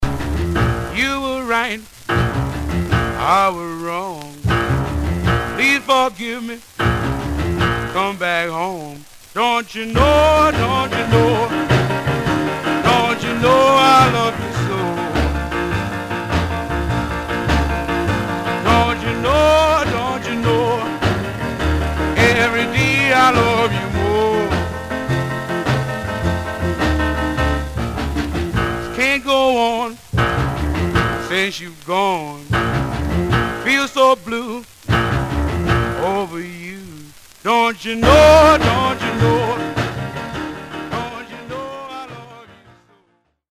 Some surface noise/wear
Mono
Rythm and Blues Condition